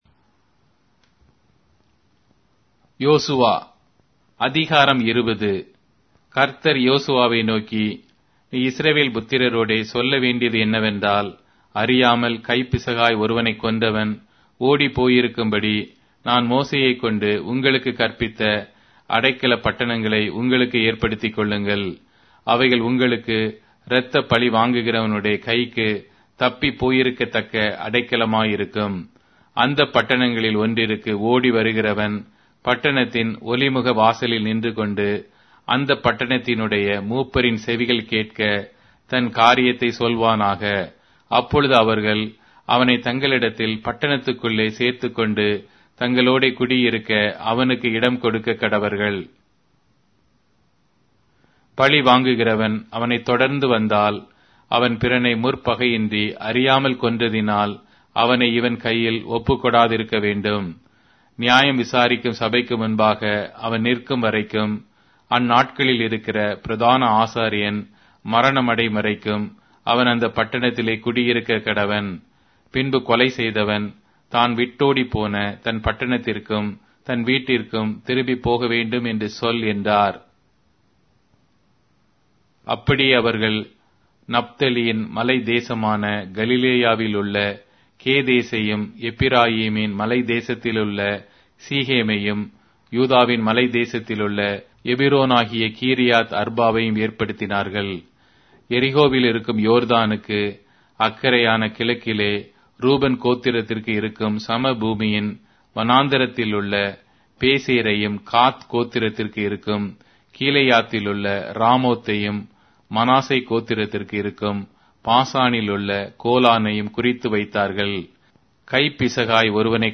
Tamil Audio Bible - Joshua 16 in Mrv bible version